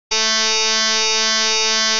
IKONKA GŁOśNIKA Przykład częstotliwości podstawowej 440 Hz i modulującej 220 Hz